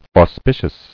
[aus·pi·cious]